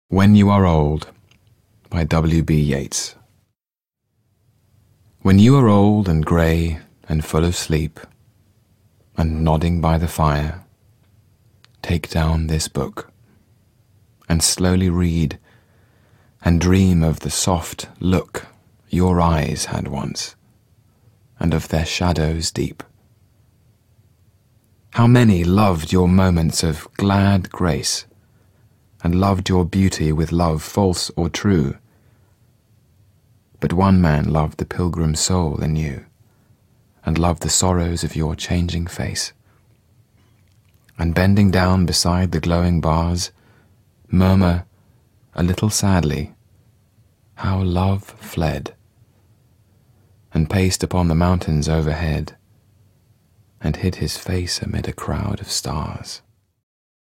听Tom Hiddleston朗诵的W.B. Yeats著名诗篇When you are old
When You Are Old read by Tom Hiddleston（流畅）.mp3
1. you are两个词发生弱化，发音极为短促。
4. old采用了升调。
1. 弱读的几个词and, and, of, the——轻、快、弱、低。
2. read一词的长元音长、饱满、展唇。